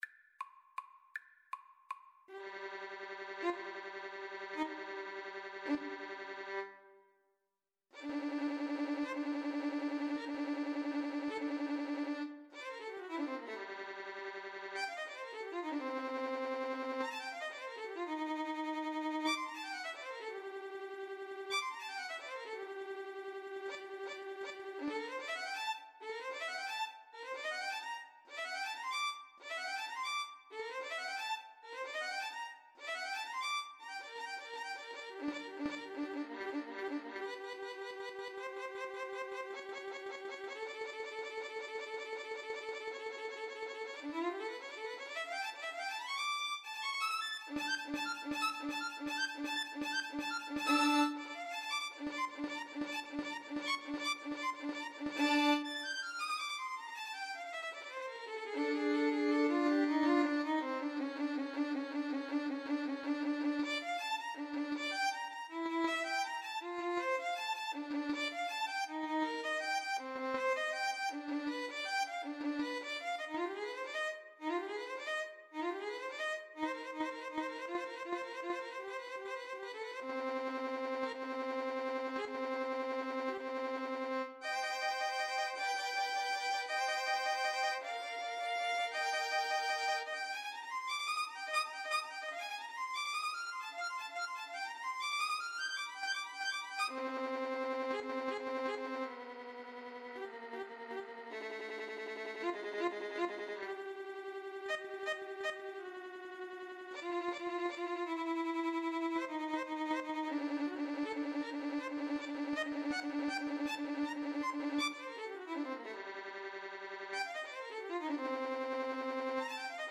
Free Sheet music for Violin-Cello Duet
Bb major (Sounding Pitch) (View more Bb major Music for Violin-Cello Duet )
3/4 (View more 3/4 Music)
III: Presto (View more music marked Presto)
Classical (View more Classical Violin-Cello Duet Music)